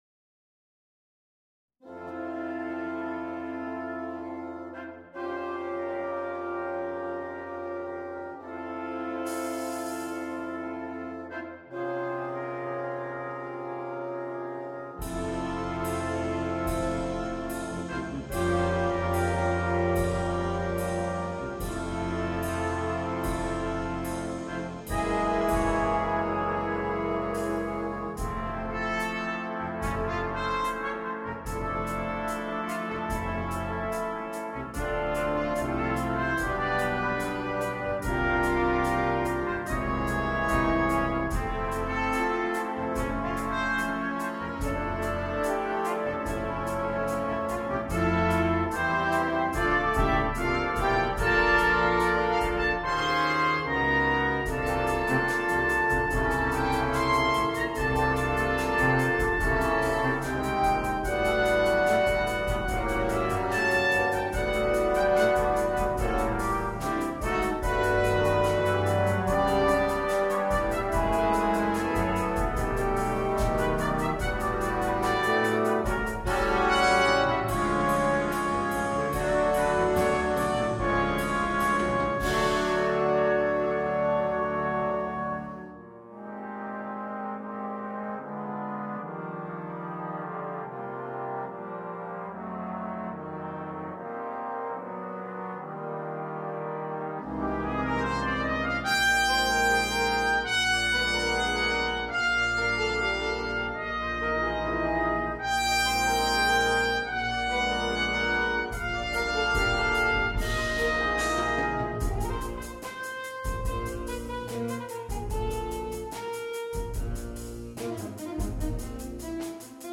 для биг-бэнда.